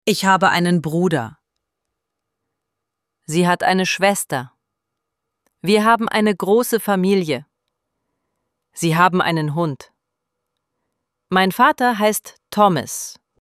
IZGOVOR – PRIMJERI:
ElevenLabs_Text_to_Speech_audio-43.mp3